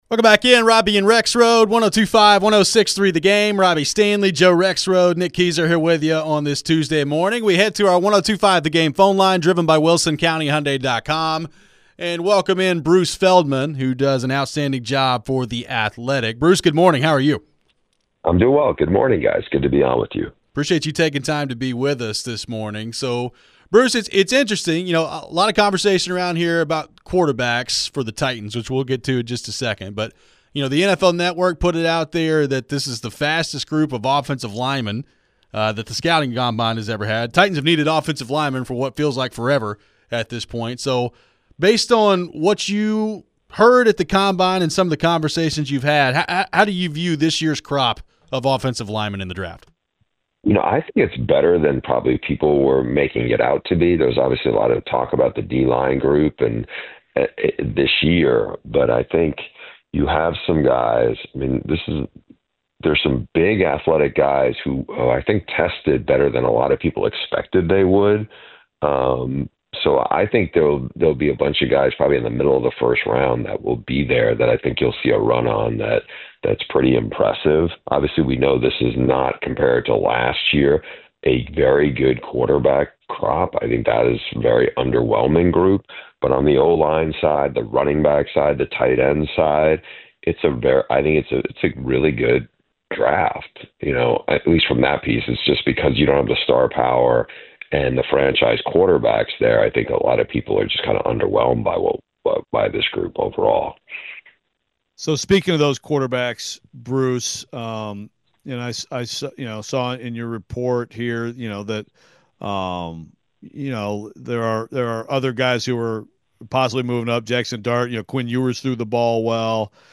Bruce Feldman Interview (3-4-25)